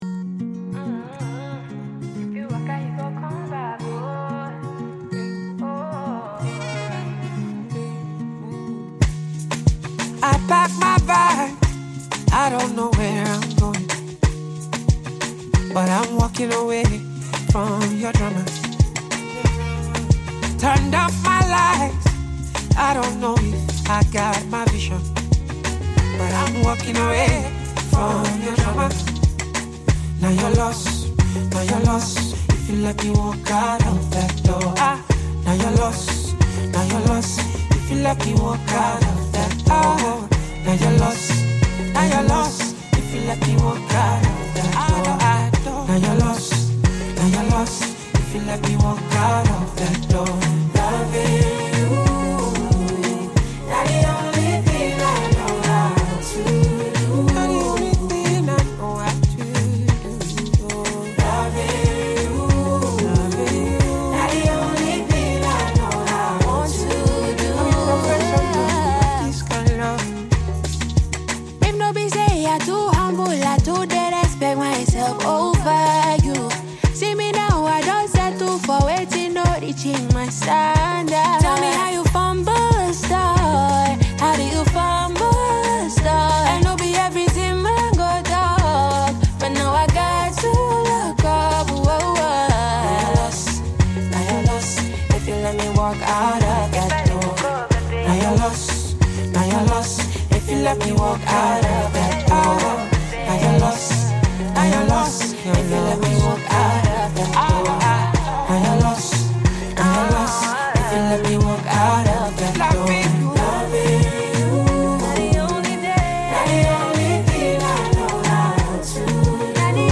Nigerian / African Music
African Music Genre: Afrobeats Released